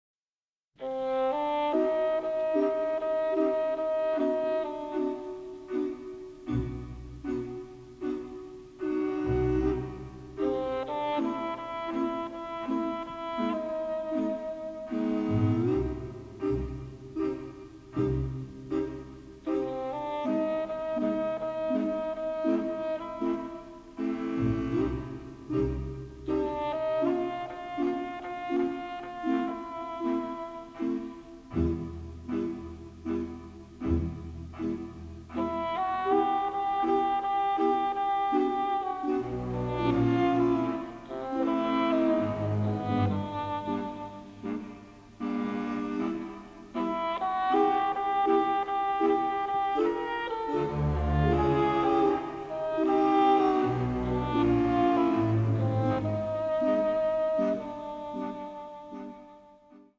The Original Album (stereo)